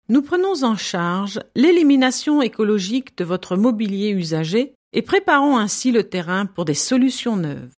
sprecherdemos
französisch w_02